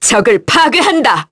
Priscilla-Vox_Skill7_kr.wav